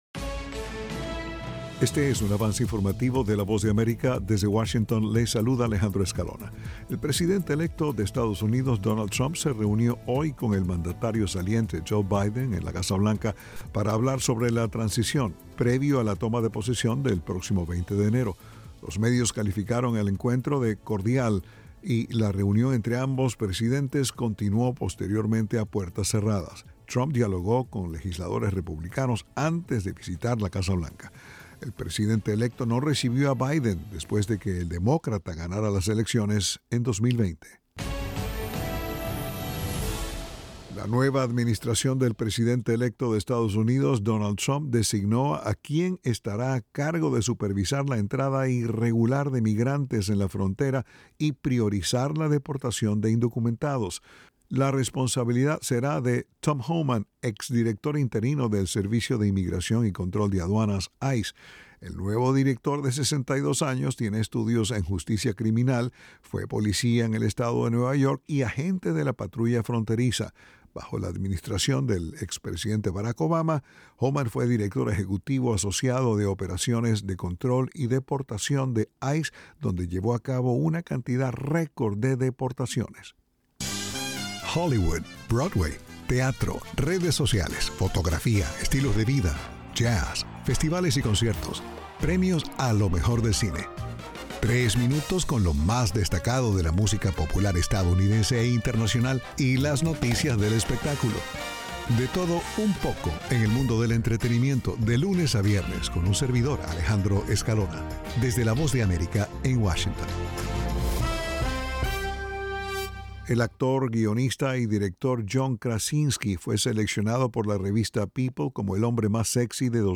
El siguiente es un avance informativo presentado por la Voz de America en Washington.